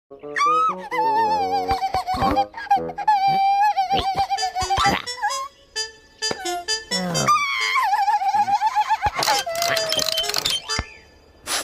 Funfetti Crying Sound Effects Free Download